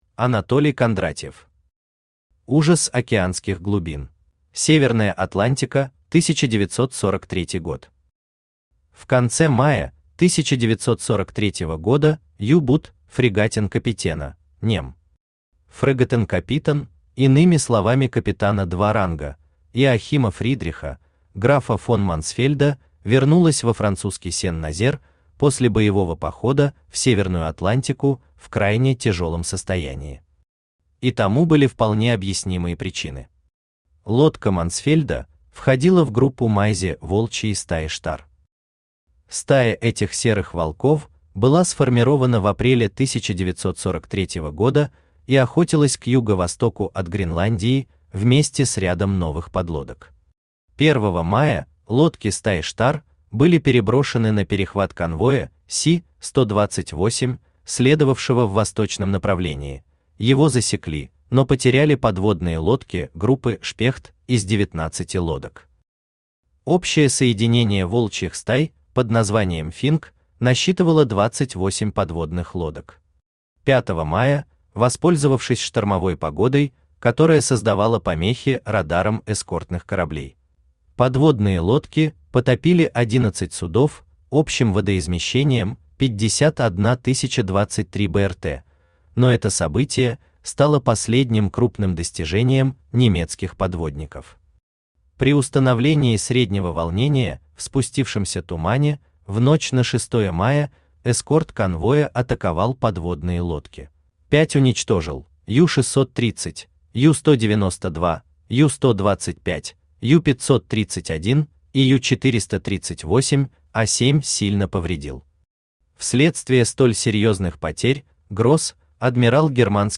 Аудиокнига Ужас океанских глубин | Библиотека аудиокниг
Aудиокнига Ужас океанских глубин Автор Анатолий Васильевич Кондратьев Читает аудиокнигу Авточтец ЛитРес.